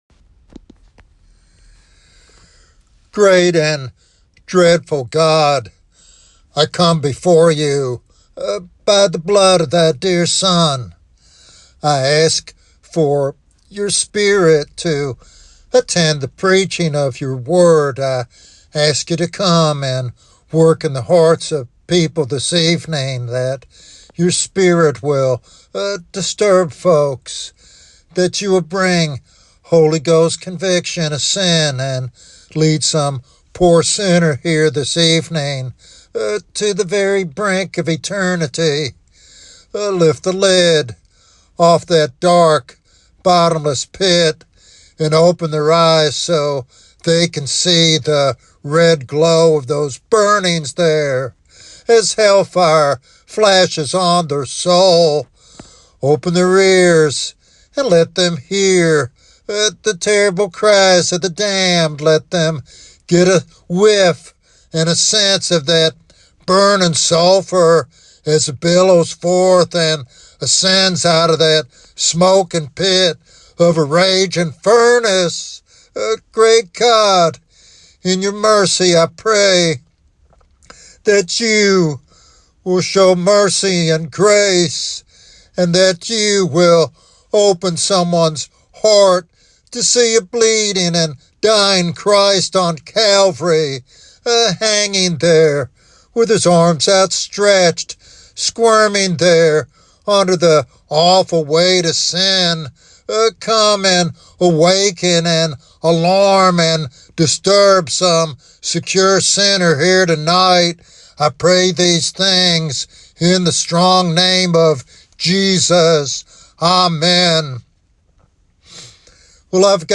With heartfelt urgency, he calls sinners to repent and embrace the free gift of salvation before the final judgment.